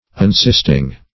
Unsisting \Un*sist"ing\, a.